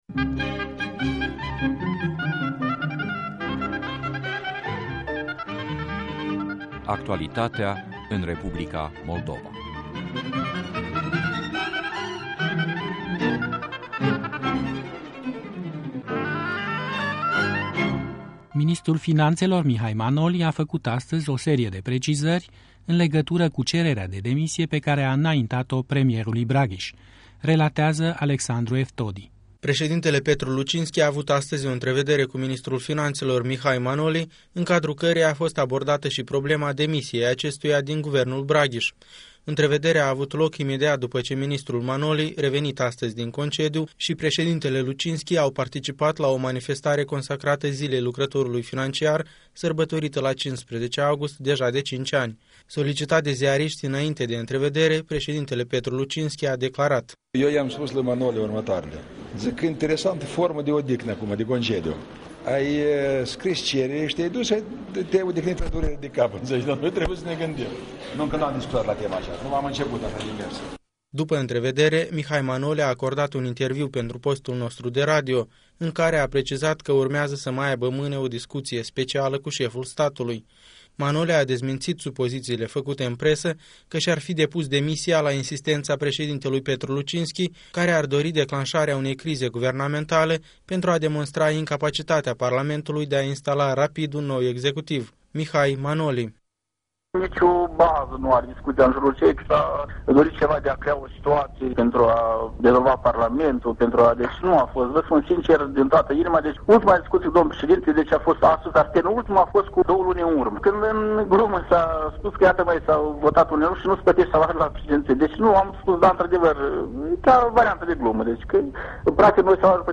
Un interviu cu ministrul de finanțe Mihail Manoli